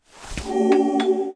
revive_someone.wav